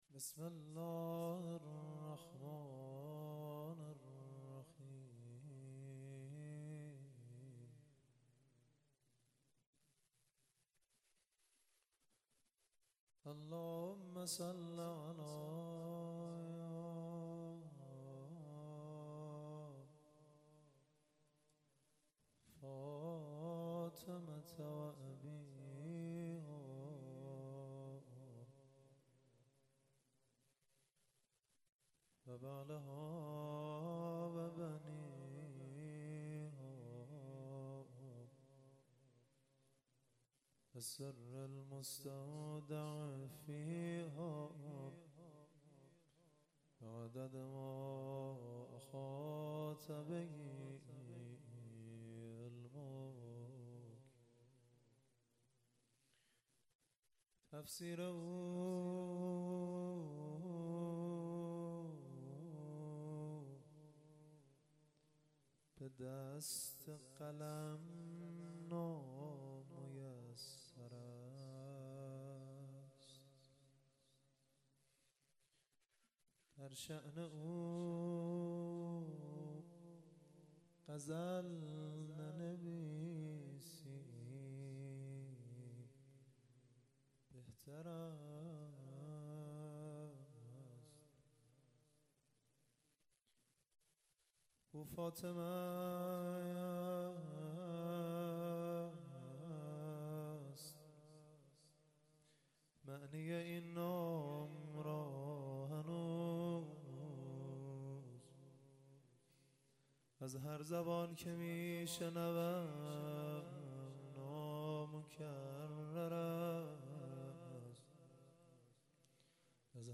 روضه حضرت زهرا